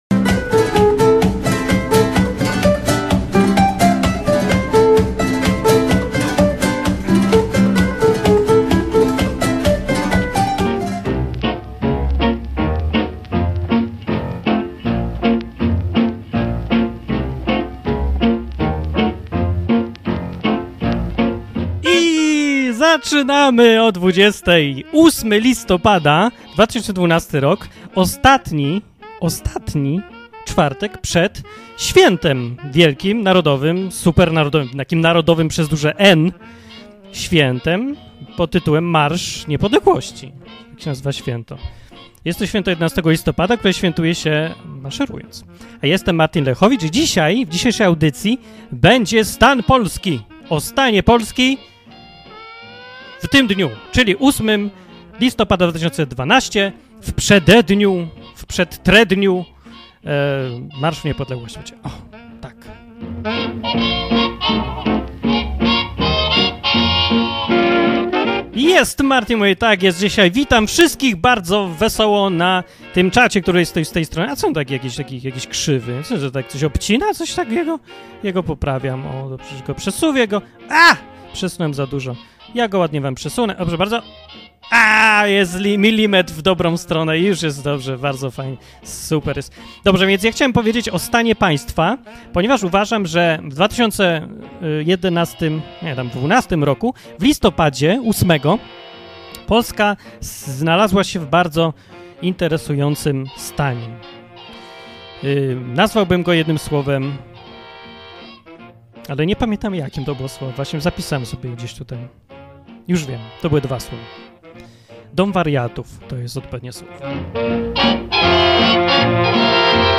Były informacje, komentarze, słuchacze, wszystko co najlepsze.